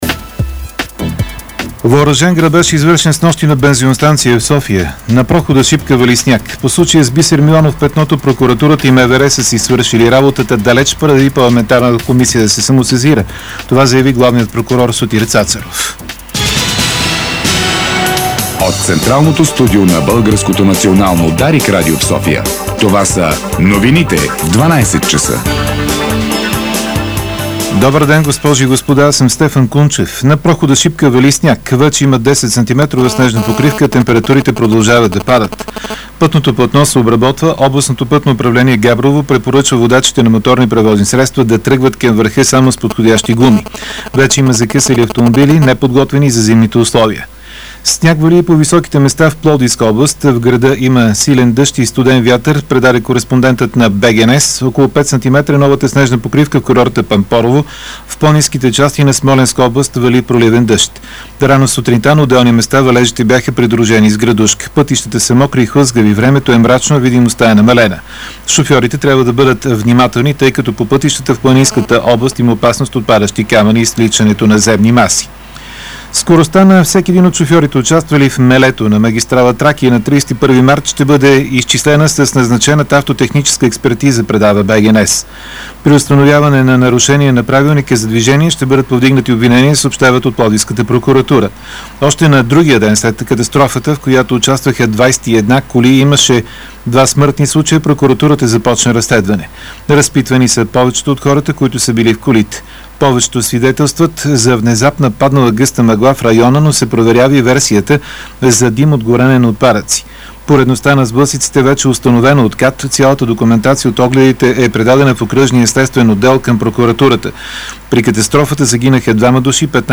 Обедна информационна емисия